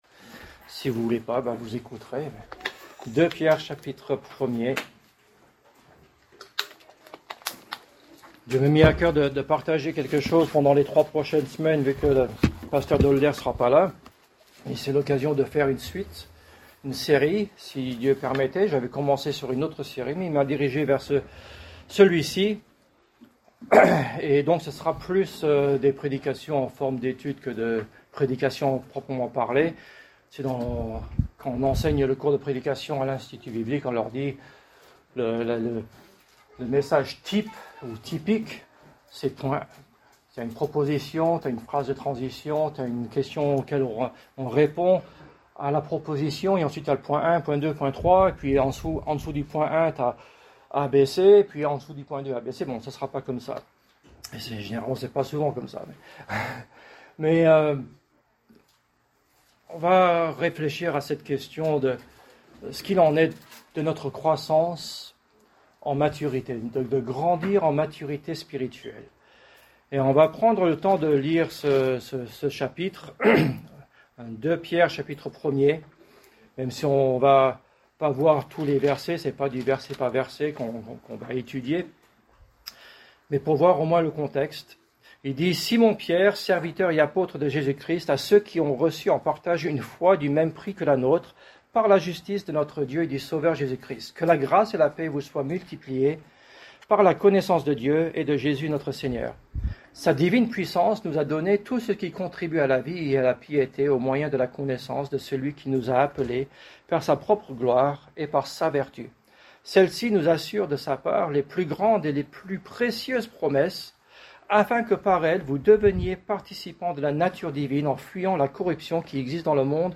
Genre: Prédication